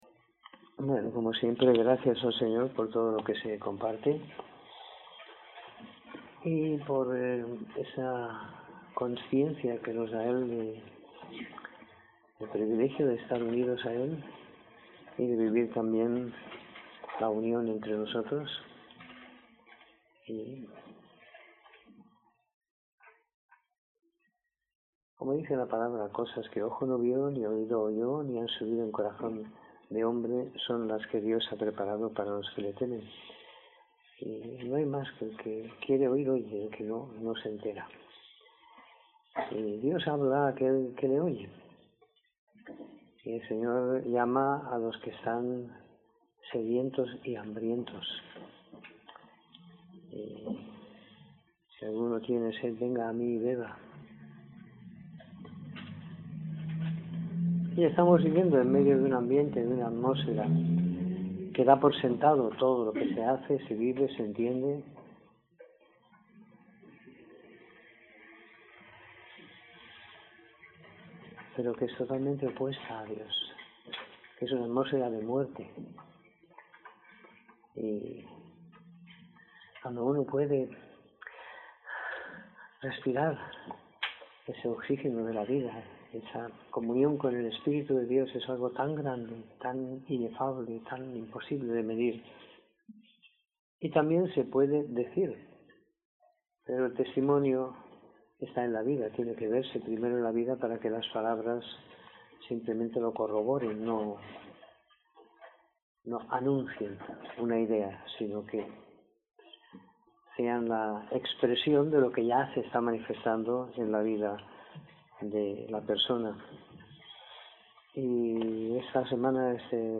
Reunión del Viernes